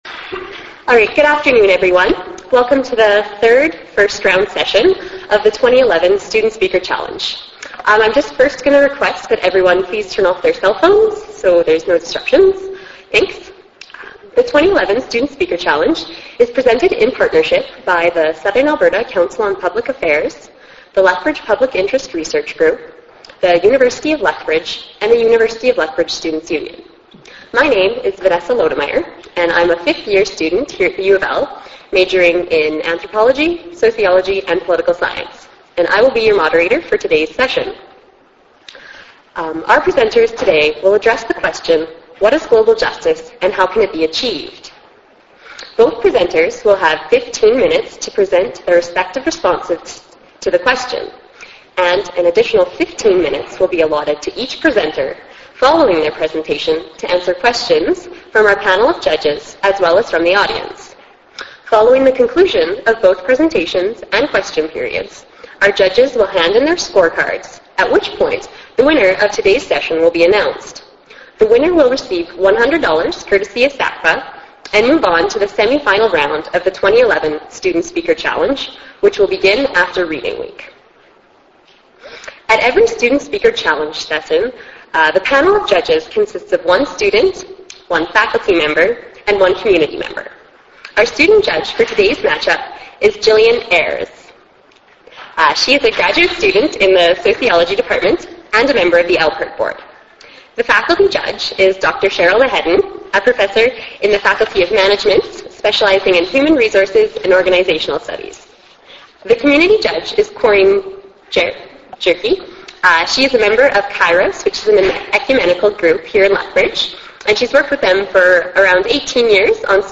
January 18 – March 15, 2011 Ballroom A in the SU building and at Andy’s Place (AH 100) University of Lethbridge The third annual Student Speaker Challenge will kick off on Tuesday, Jan. 18 with a total of eight students competing in this year’s event, addressing the question: What is global justice and how can it be achieved?